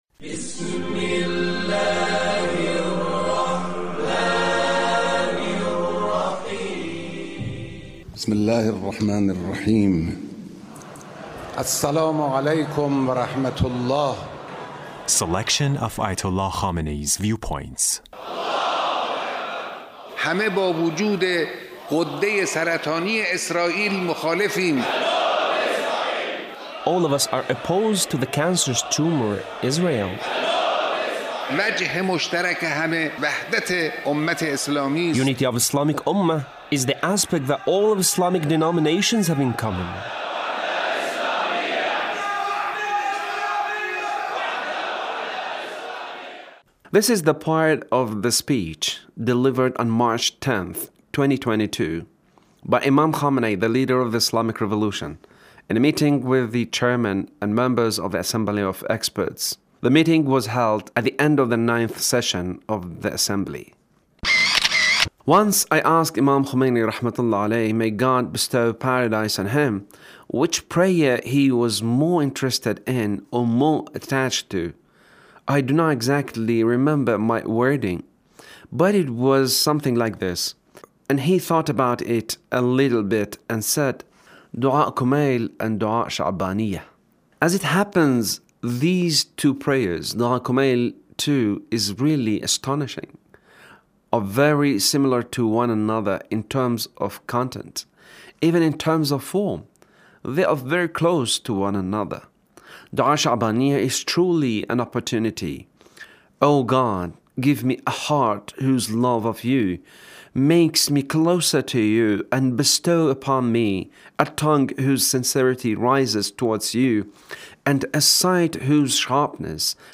The Leader's speech about the month of Shaban